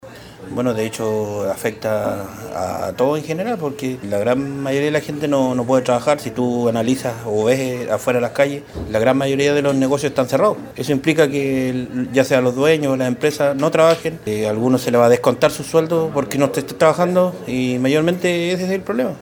Uno de los empleados del comercio céntrico, comentó lo difícil de la situación, explicando que muchas personas quedarán sin sueldos ya que algunos empleadores no deciden abrir sus negocios.